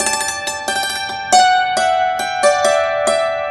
Dulcimer11_137_G.wav